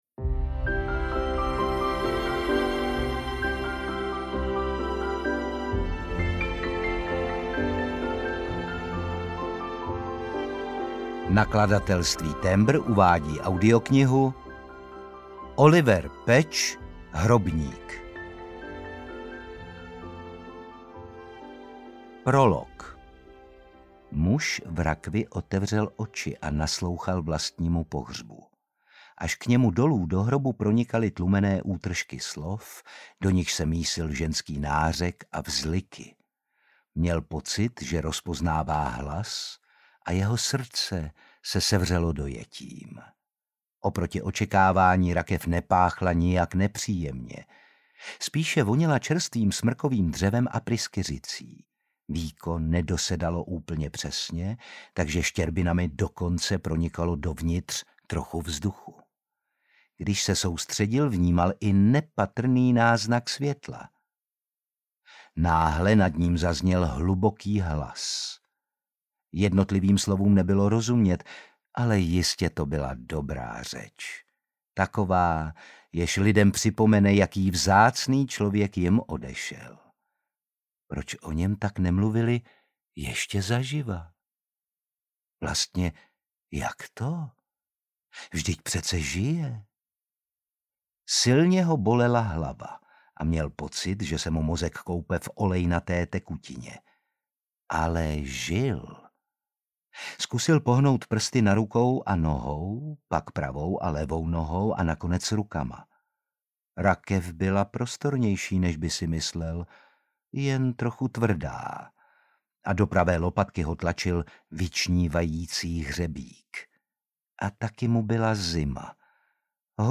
Hrobník audiokniha
Ukázka z knihy
• InterpretOtakar Brousek ml.